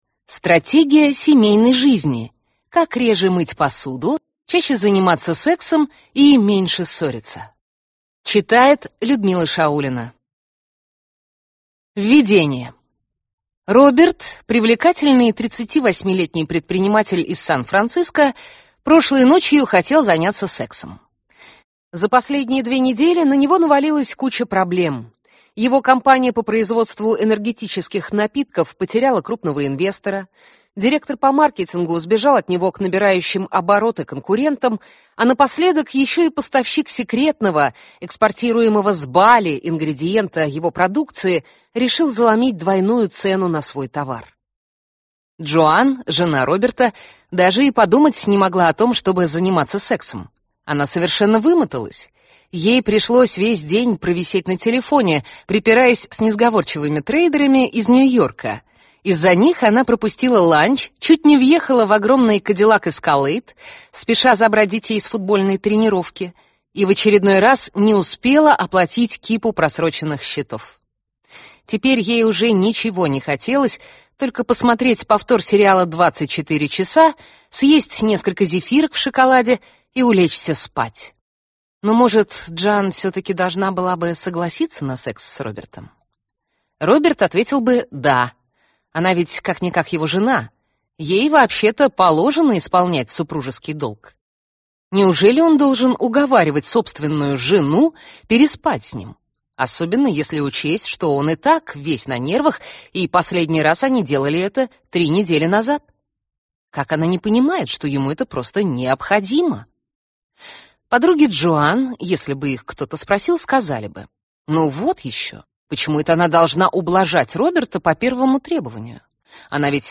Аудиокнига Стратегия семейной жизни. Как реже мыть посуду, чаще заниматься сексом и меньше ссориться | Библиотека аудиокниг